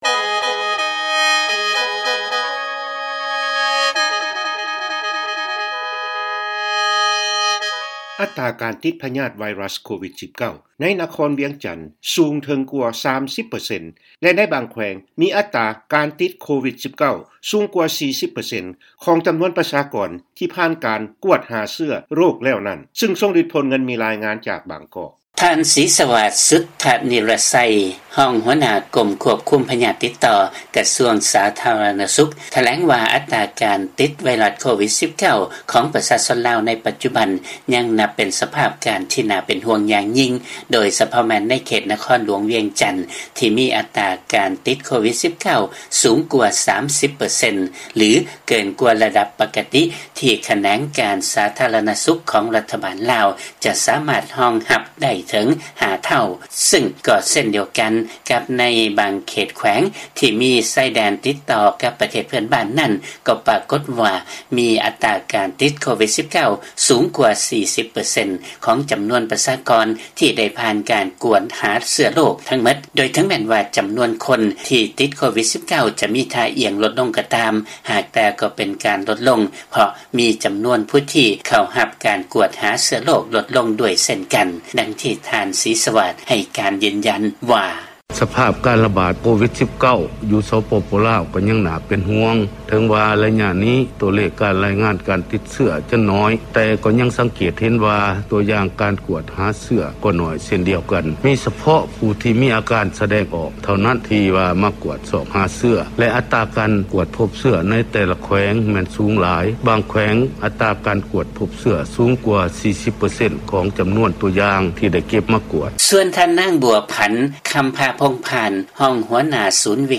ເຊີນຟັງລາຍງານກ່ຽວກັບອັດຕາການຕິດພະຍາດໂຄວິດ-19 ໃນລາວ